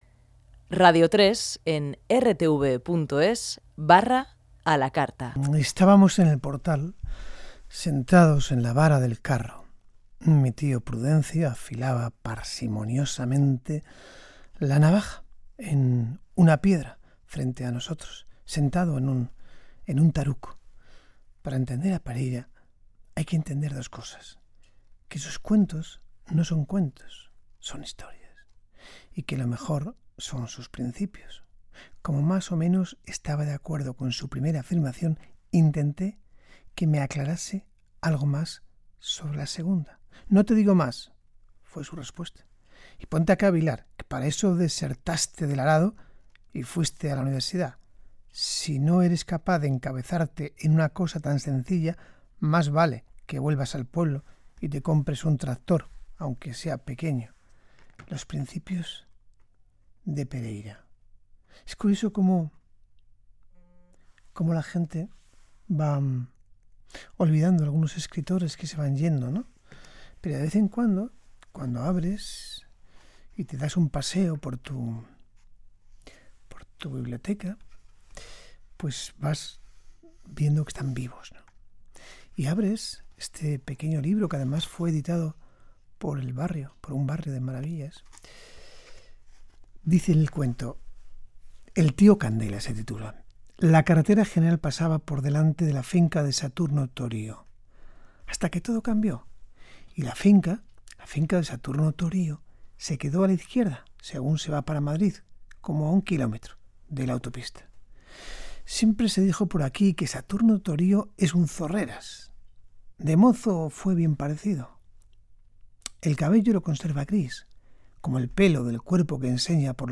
Lectura de un cuento de Antonio Pereira